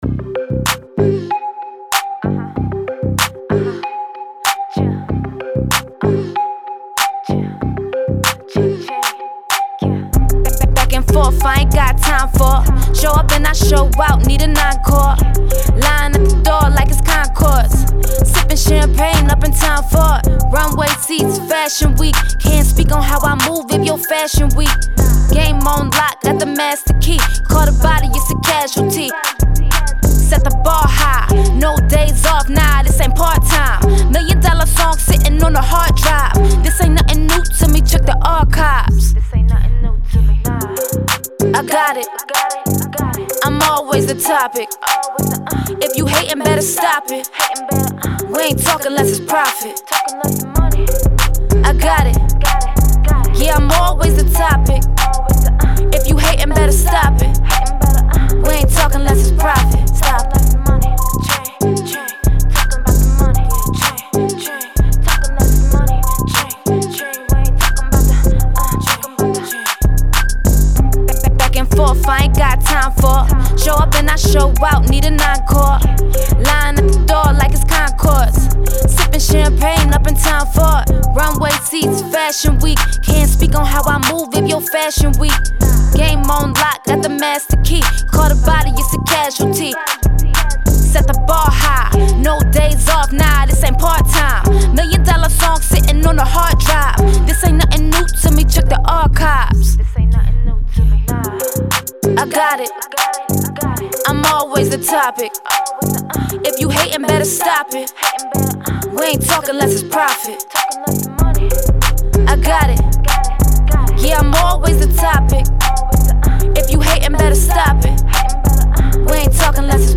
Hip Hop
C Minor
A female rap song showcasing confidence, swag and fashion.